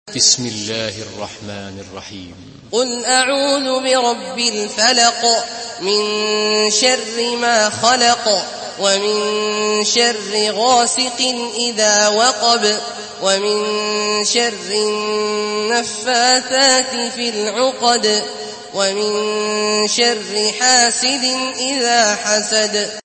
Surah Felak MP3 in the Voice of Abdullah Al-Juhani in Hafs Narration
Surah Felak MP3 by Abdullah Al-Juhani in Hafs An Asim narration.
Murattal Hafs An Asim